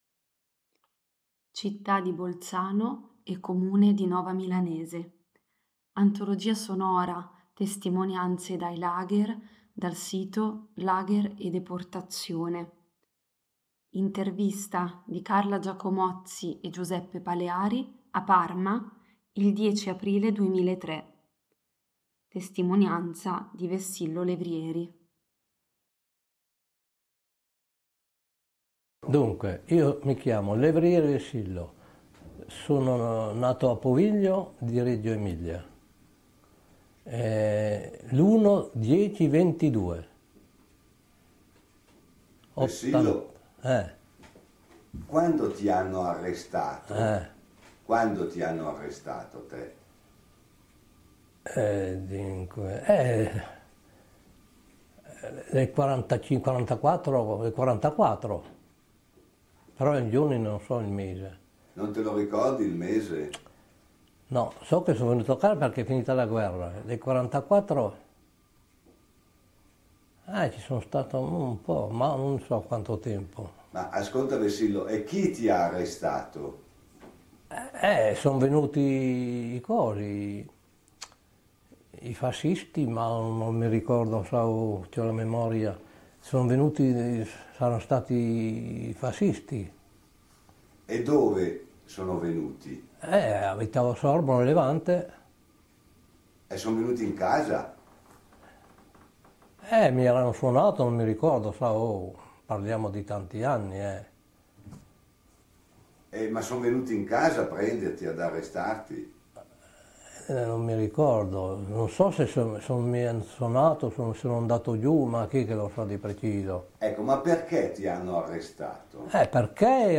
Intervista del 10/04/2003, a Parma